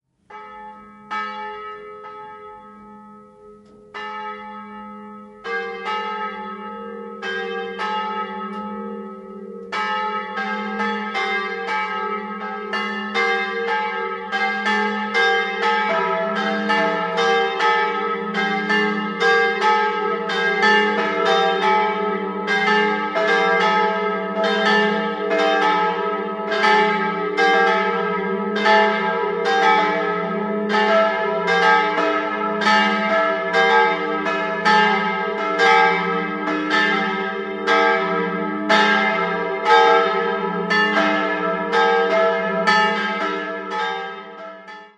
3-stimmiges TeDeum-Geläute: e'-g'-a' Herz-Jesu-Glocke e' 121 cm 1884 Eduard Becker, Ingolstadt Marienglocke g' 99 cm 1958 Karl Czudnochowsky, Erding Josefsglocke a' 91 cm 1922 Heinrich Ulrich, Apolda/Kempten